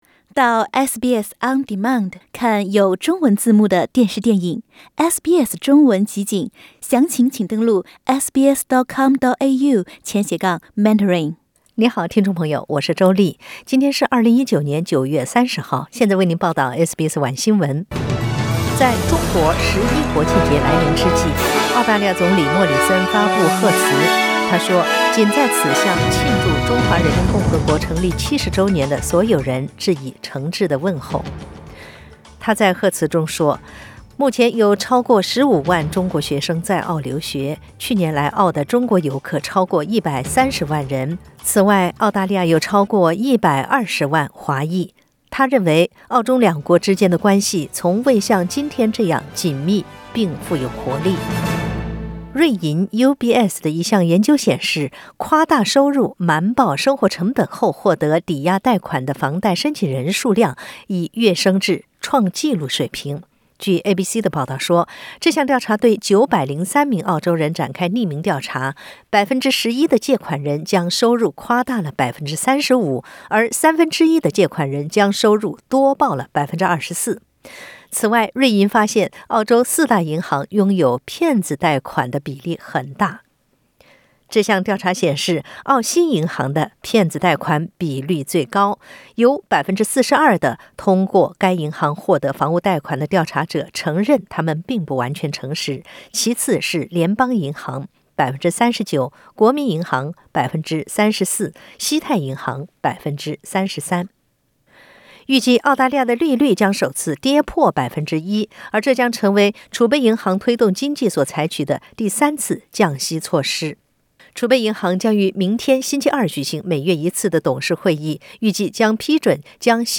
SBS 晚新闻 （9月30日）